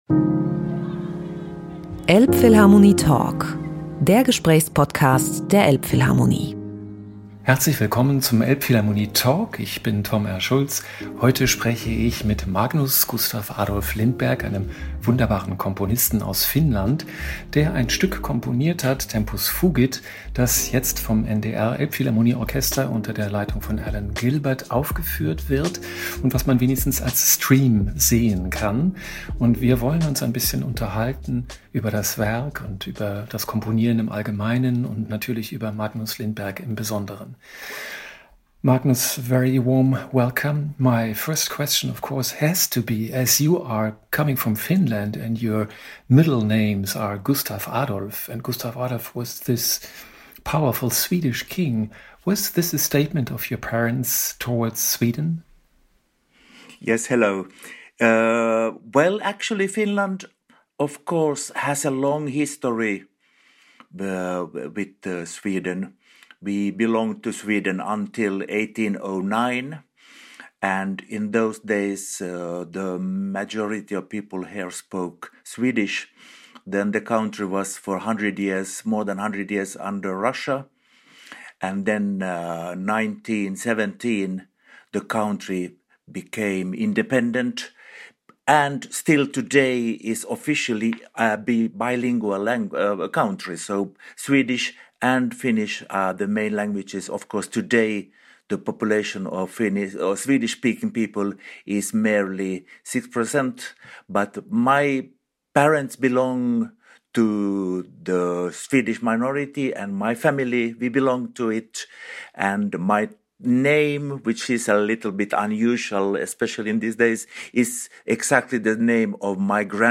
elbphilharmonie-talk-mit-magnus-lindberg-mmp.mp3